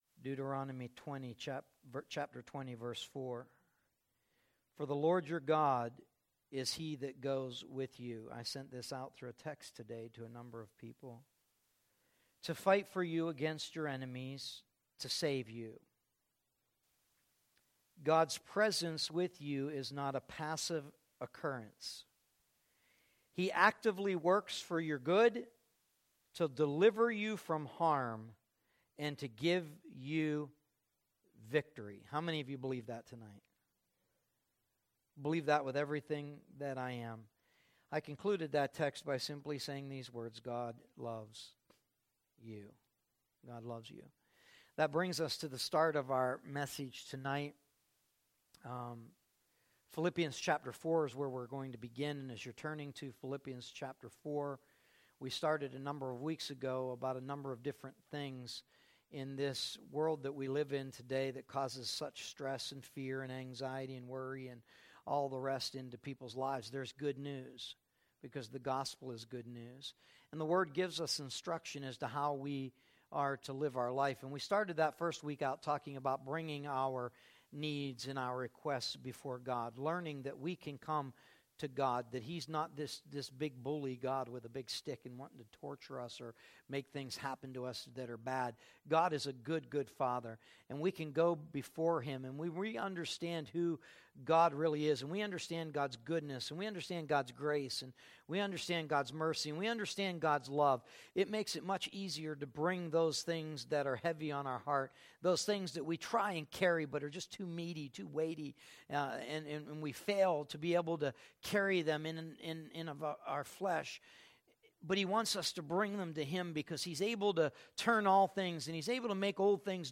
“180221_0644.mp3” from TASCAM DR-05.